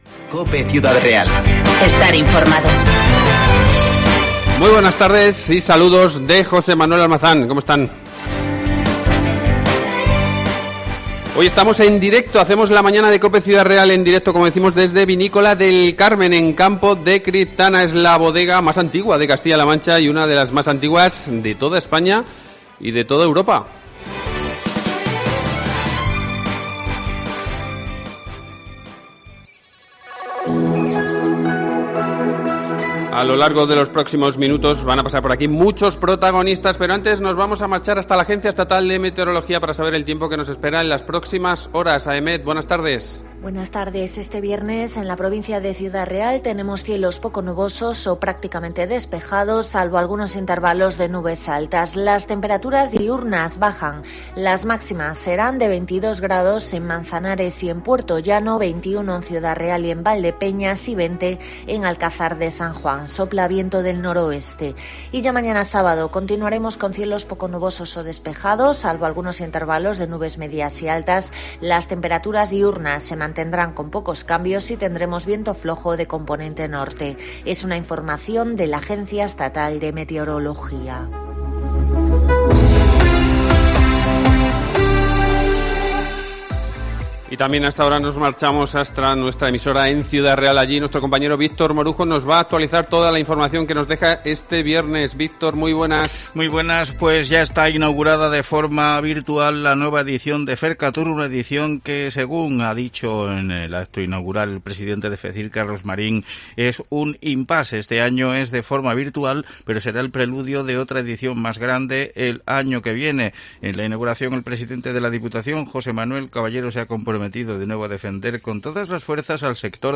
La Mañana de COPE Ciudad Real desde la Cooperativa Vinícola del Carmen, de Campo de Criptana (primera parte)